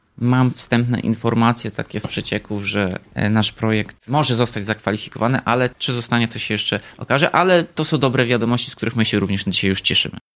Prace mają ruszyć w przyszłym roku – jeżeli wcześniej uda się na nie pozyskać fundusze z zewnątrz. Prezydent miasta Tomasz Andrukiewicz zdradza, że szanse na to są duże.